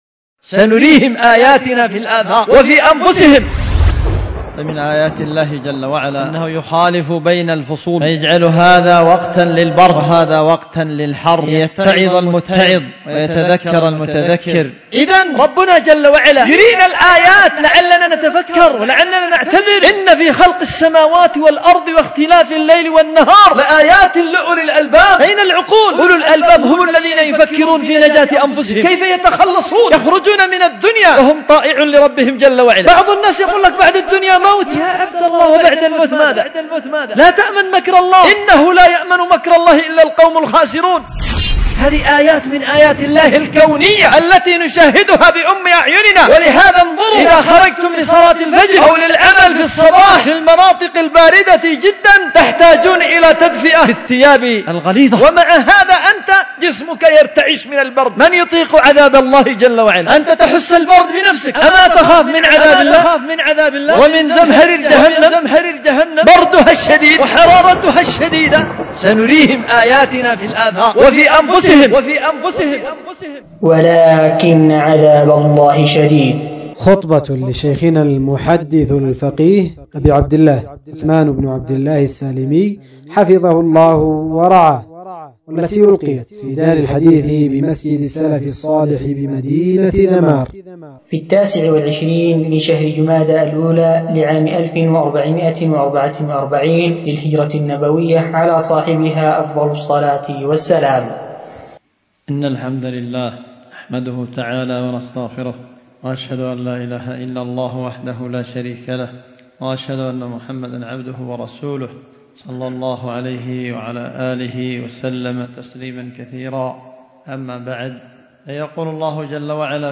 خطبة
في دار الحديث بمسجد السلف الصالح بذمار